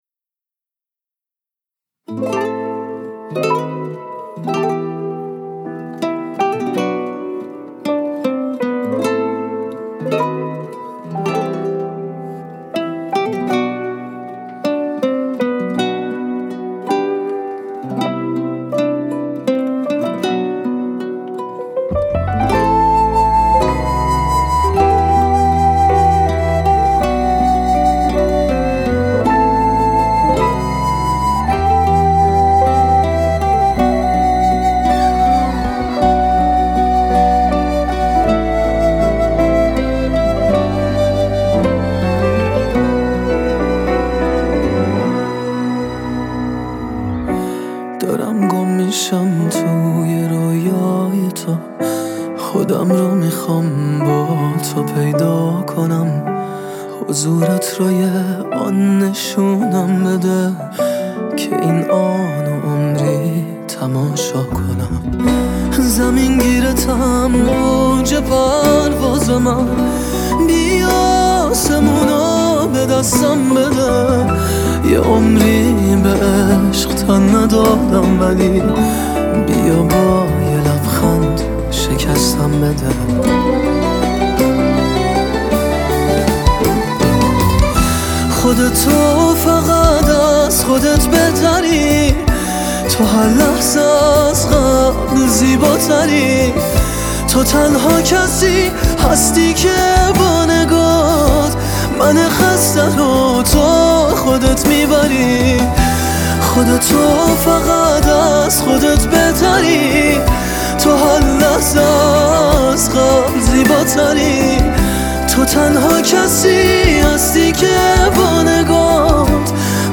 با ریتم 6/8